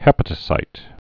(hĕpə-tə-sīt, hĭ-pătə-)